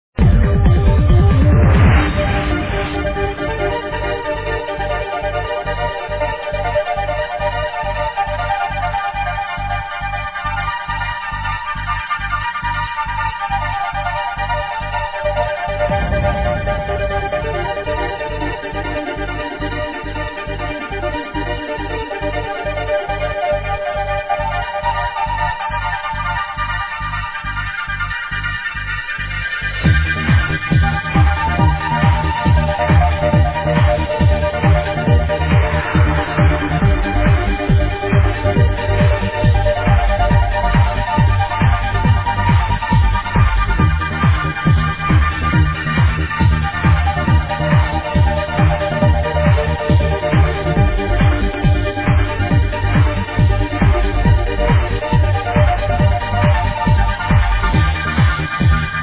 AMAZING TRANCE track ID needed